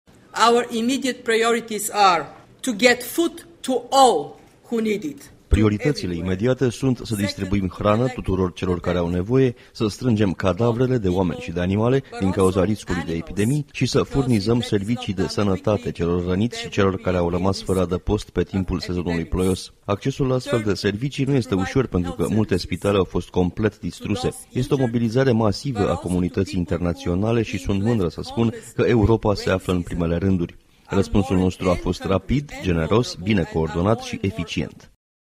taifunComisia Europeană şi tările membre ale Uniunii au acordat până acum ajutoare de circa 100 de milioane de euro pentru Filipine, unde milioane de oameni au fost afectaţi de taifunul Haiyan. Anunţul a fost făcut de comisarul pentru răspuns la situaţii de criză, Kristalina Georgieva, după ce a vizitat zonele care au avut cel mai mult de suferit :